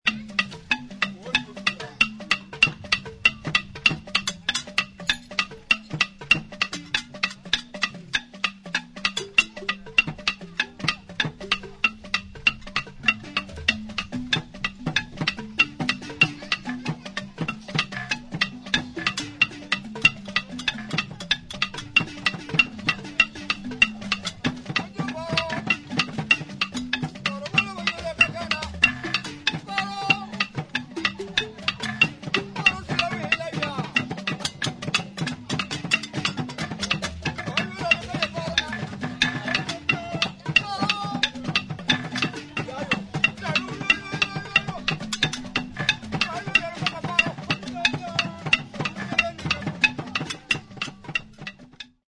The pendere is a closed pot-shaped gourd drum. The membrane, which is goat hide, is stretched over the opening of the gourd and tightened by means of ropes and tongues.
Its soft sound makes the pendere an excellent accompaniment to the xylophone. It is played with both hands and the membrane can be struck with the finger-tips or the palms of the hand, producing different timbres.